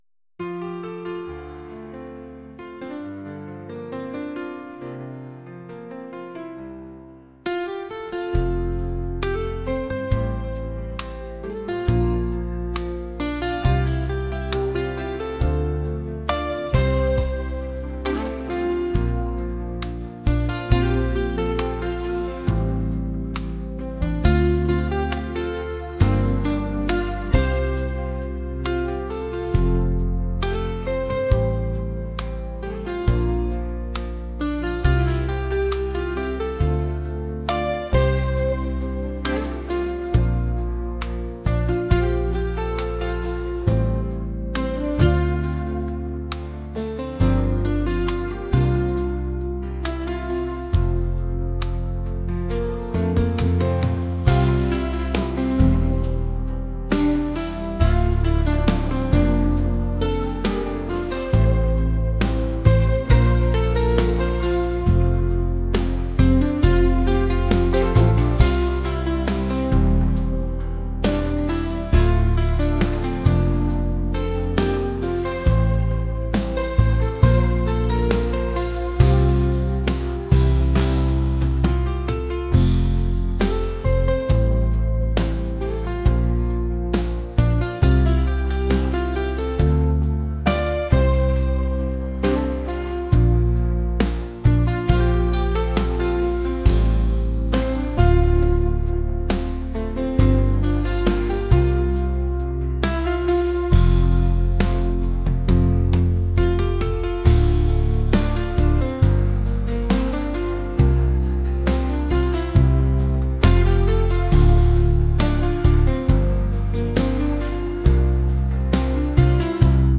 acoustic | pop | lofi & chill beats